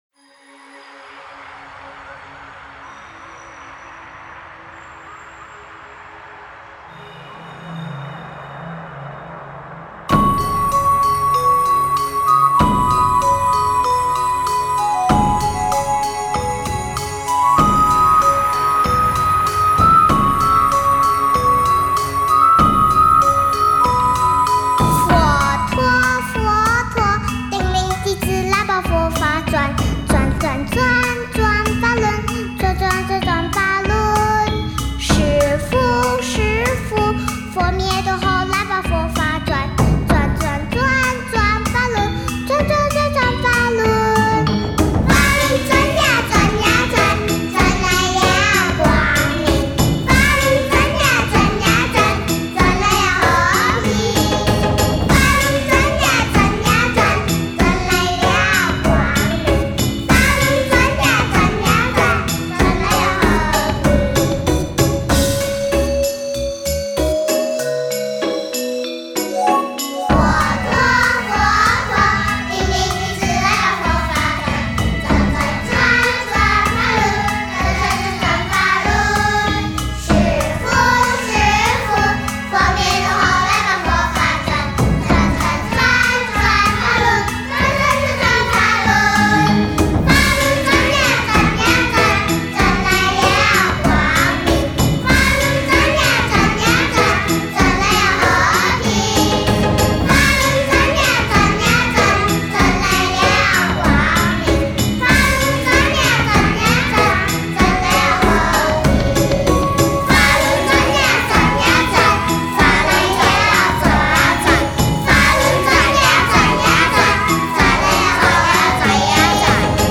★他们是一群5-6岁的幼稚园孩子，在长达5个月的训练下，生气勃勃地灌录生平第一张唱片，并旋风式地风靡了整个大马。
娇嗔的鼻音、生嫩的捲舌、认真的唱和，天真童颜唱出了无邪歌声。